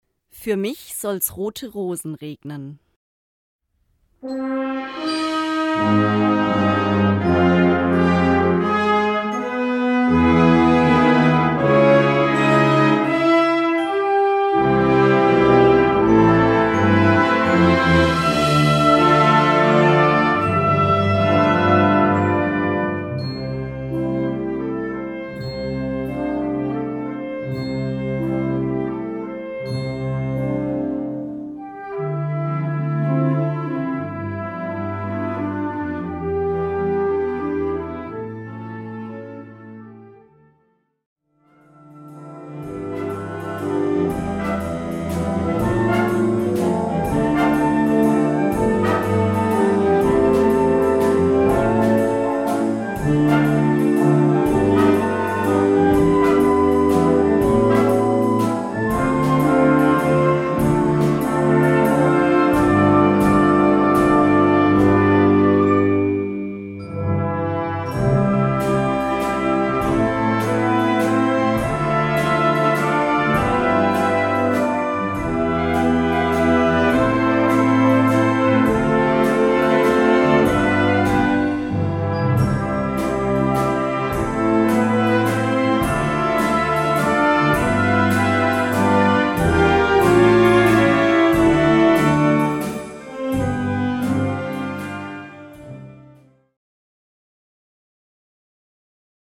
Gattung: Sologesang und Blasorchester
Besetzung: Blasorchester